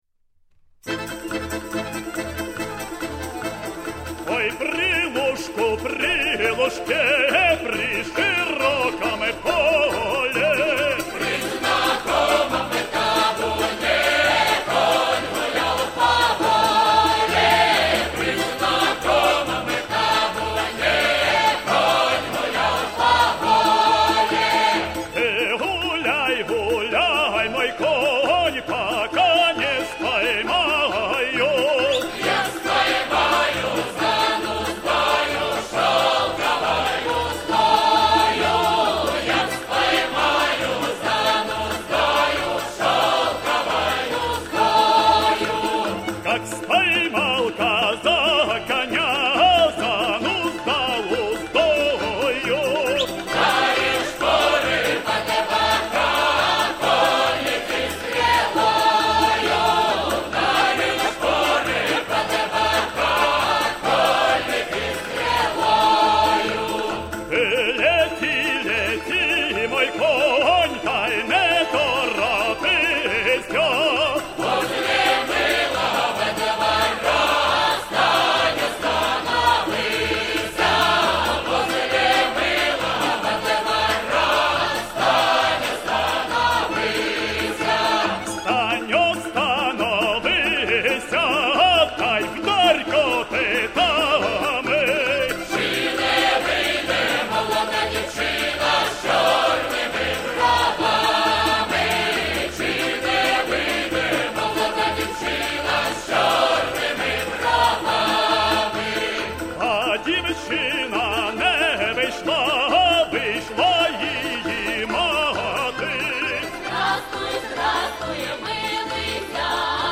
Произведения для хора